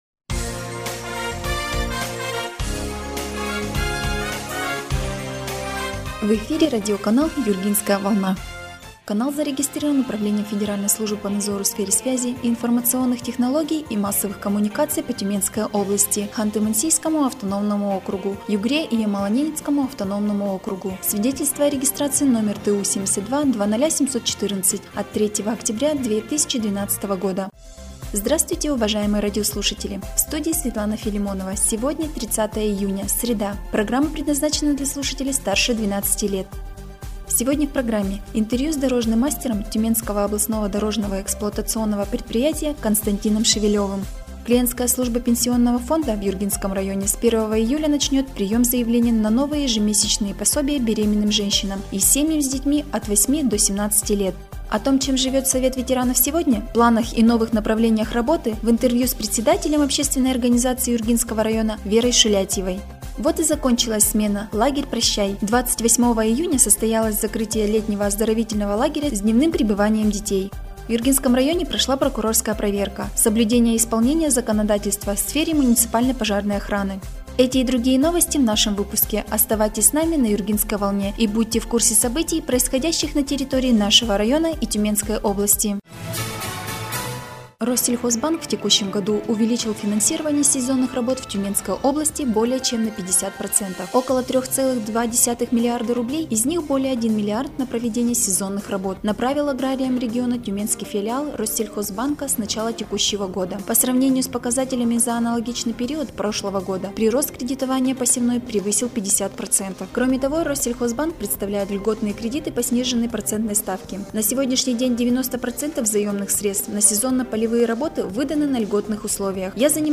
Эфир радиопрограммы "Юргинская волна" от 30 июня 2021 год